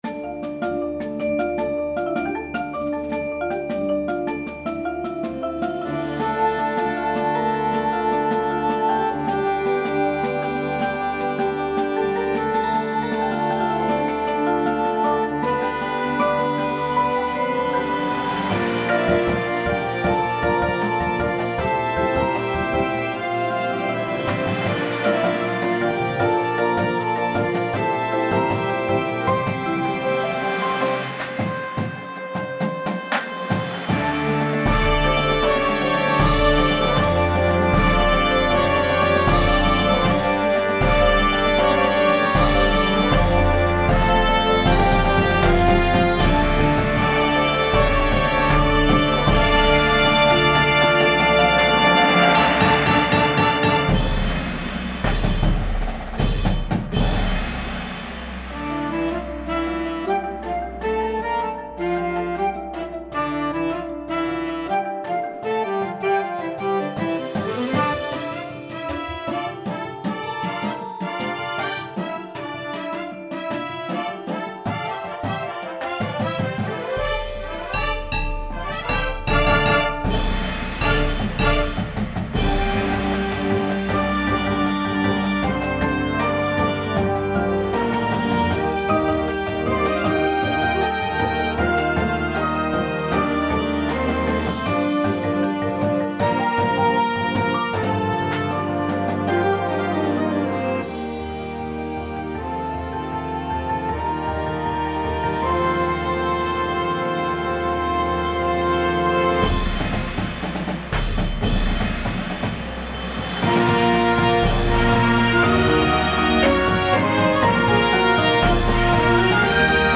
• Flute
• Clarinet 1, 2
• Alto Sax
• Trumpet 1, 2
• Horn F
• Low Brass 1, 2
• Tuba
• Snare Drum
• Tenors
• Bass Drums
• Front Ensemble